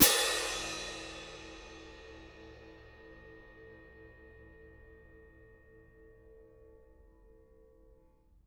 cymbal-crash1_mf_rr1.wav